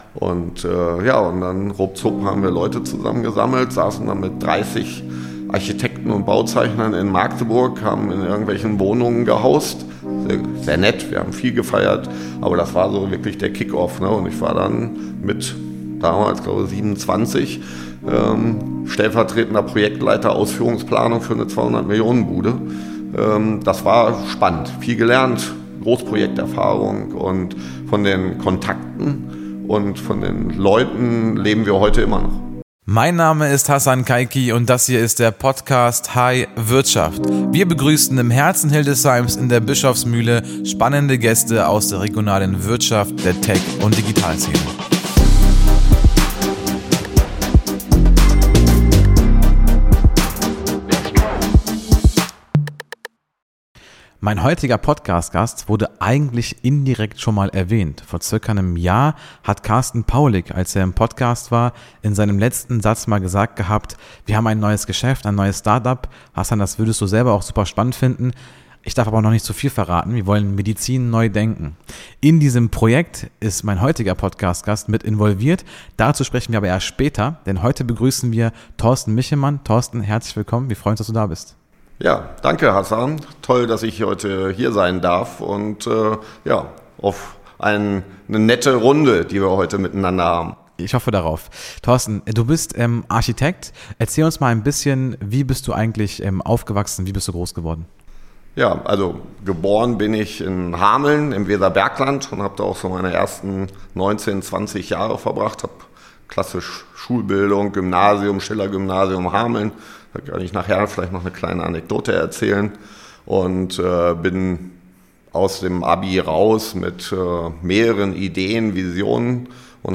In meiner aktuellen Podcast-Folge spreche ich mit einem Architekten, der genau das...